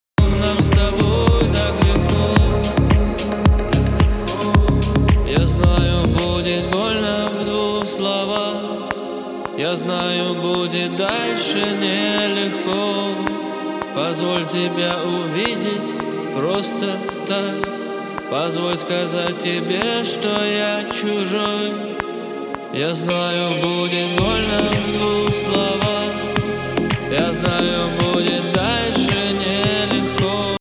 неофициальнй ремикс
Темпо -ритм вокала и аранжировки рассинхронизировался.
Вложения Metronom.mp3 Metronom.mp3 1,2 MB · Просмотры: 677